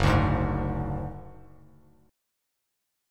Gbsus2#5 chord